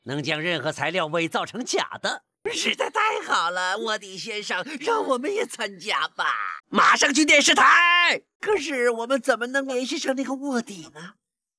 RVC模型|《开心超人联盟》大大怪将军RVC、SVC模型